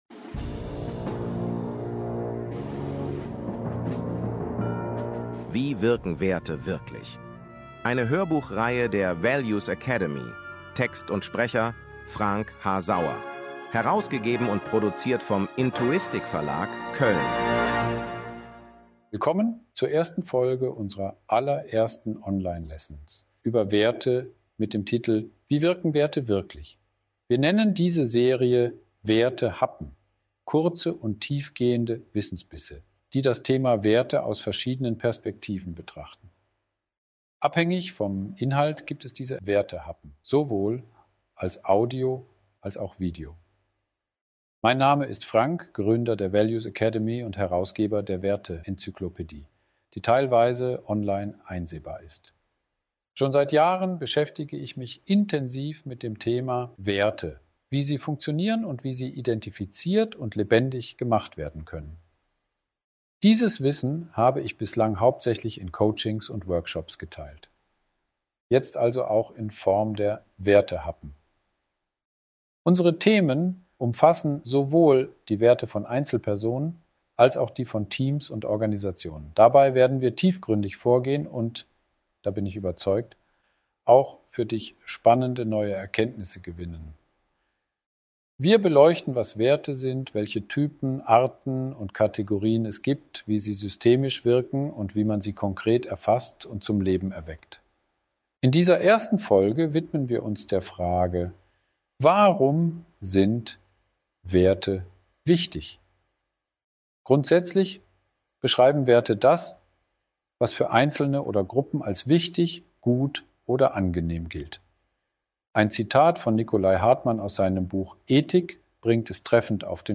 Wichtiger Hinweis: Wir nutzen teilweise KI bzw. AI zur Produktion unserer Audio und Video-Inhalte.
Audiobook – 5:22 Minuten